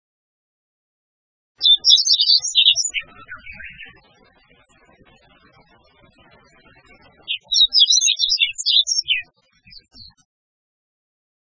〔ホオアカ〕チッチンチチチュチチュ（さえずり）／草原や潅木林などに棲息，普通・